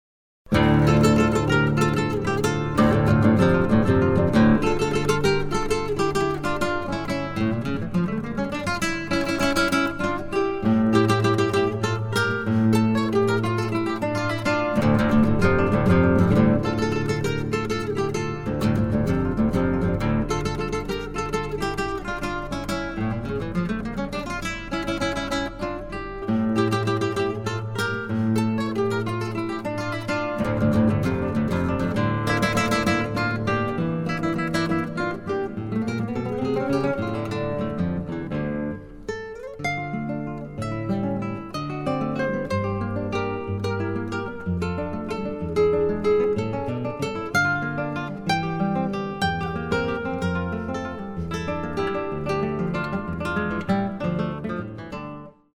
DÚO DE GUITARRAS